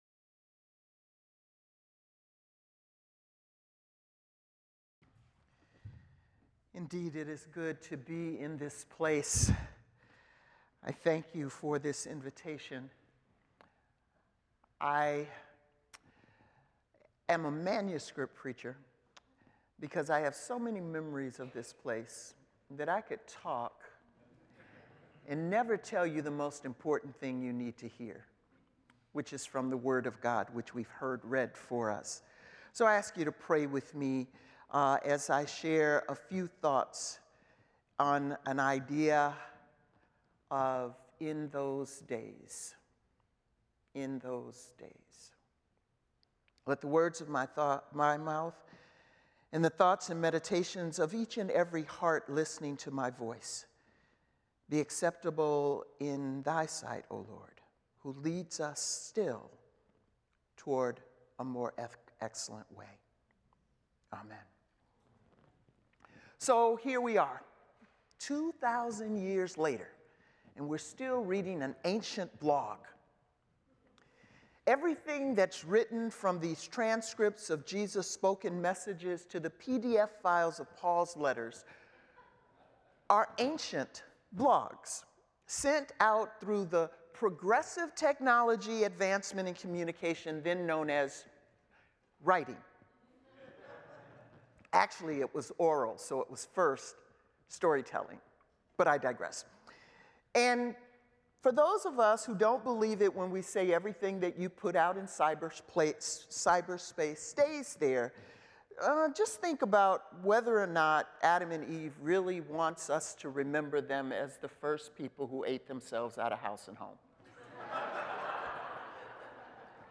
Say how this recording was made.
Chapel at Estes